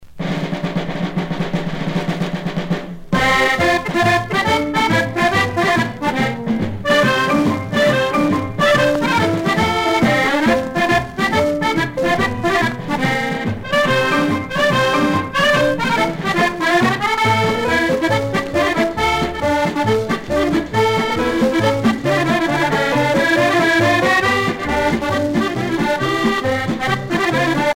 paso musette
Pièce musicale éditée